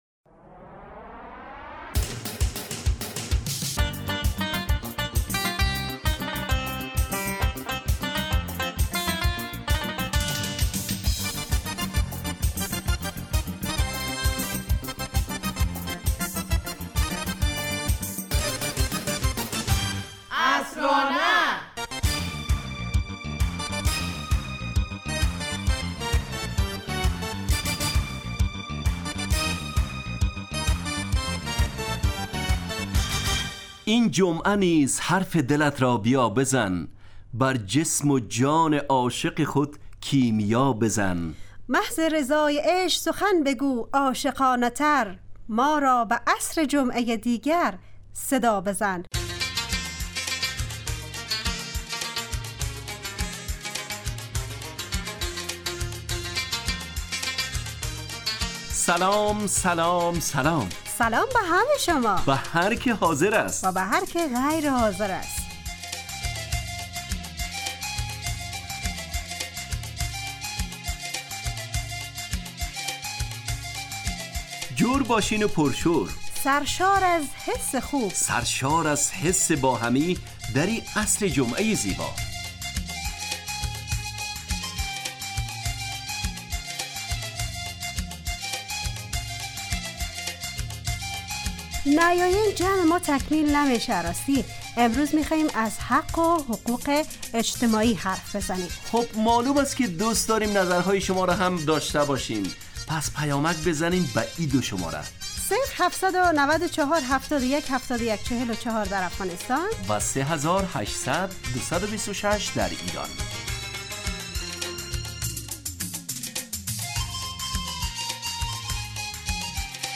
عصرانه برنامه ایست ترکیبی نمایشی که عصرهای جمعه بمدت 35 دقیقه در ساعت 17:25 دقیقه به وقت افغانستان پخش می شود و هرهفته به یکی از موضوعات اجتماعی و فرهنگی م...